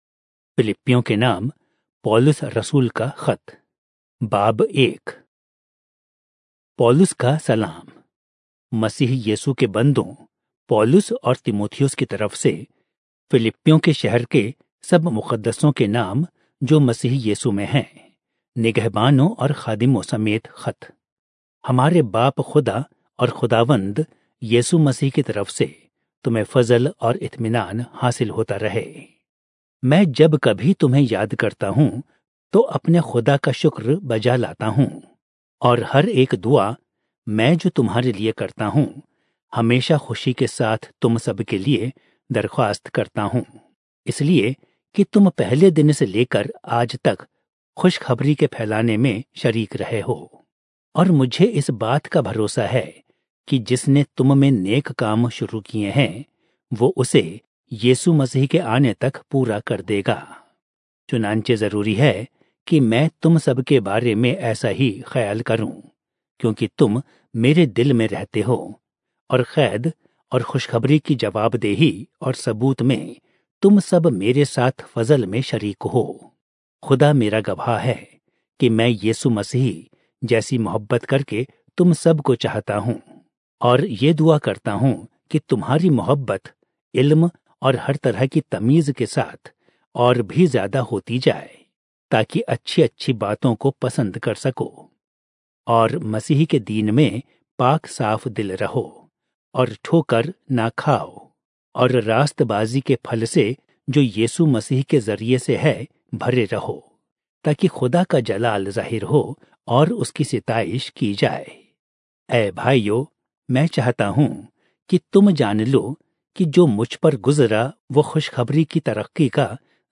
Urdu Audio Bible - Philippians All in Irvur bible version